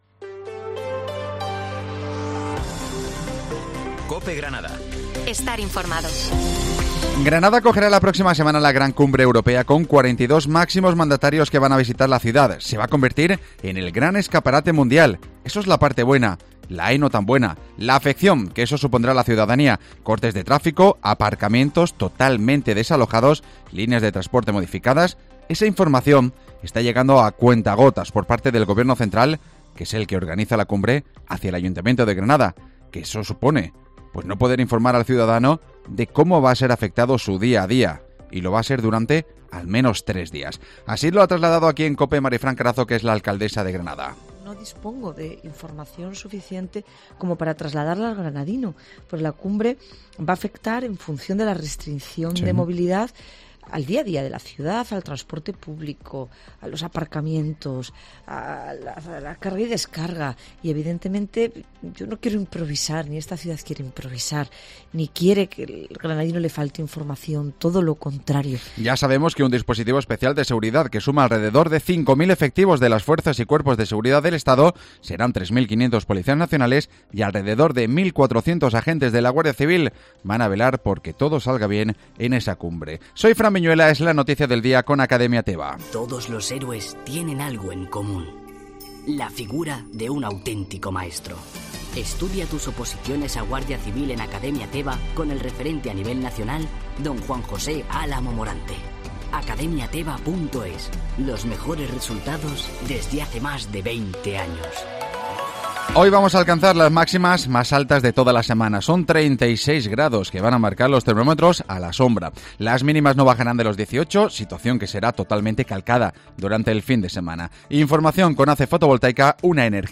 Informativo Herrera en COPE Granada - 29 septiembre